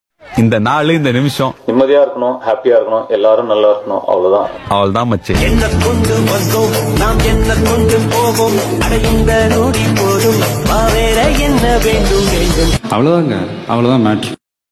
My 3rd performance Tamil Nadu sound effects free download